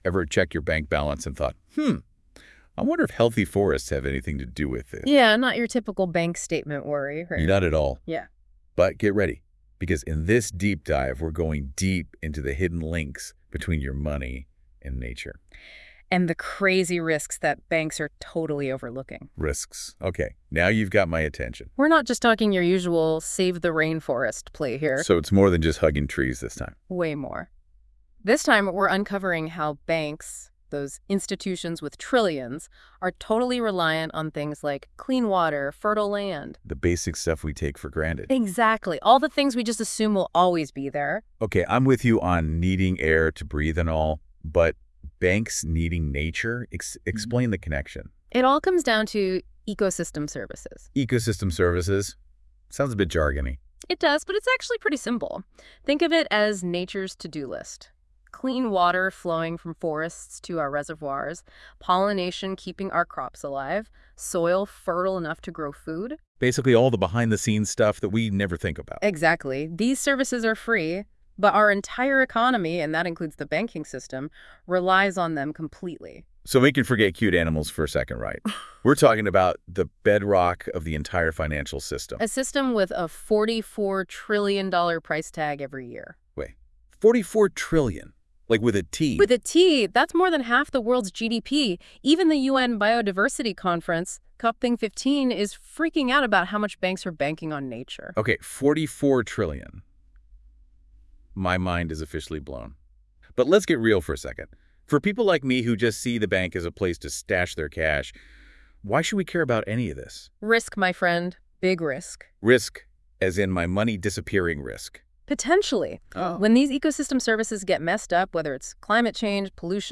AI-generated